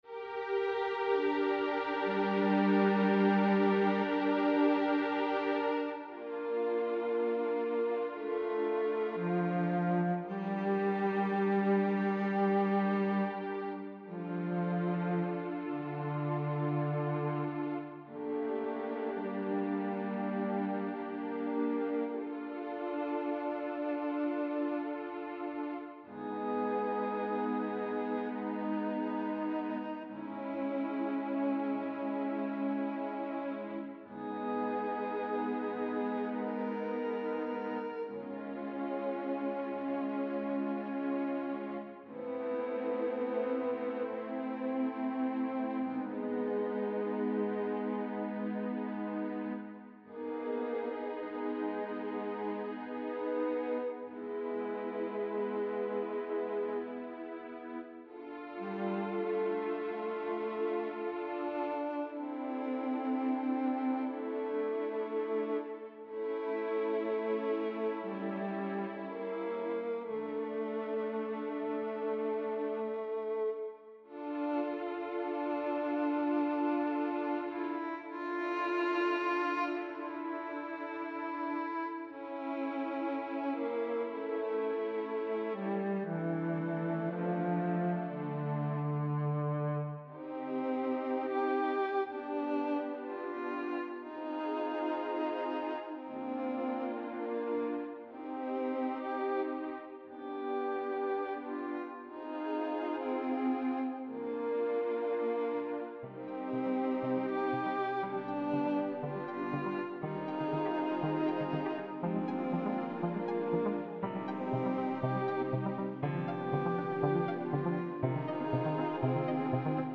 Ce truc si mélancolique, moi qui aime tant rire ?